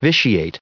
added pronounciation and merriam webster audio
752_vitiate.ogg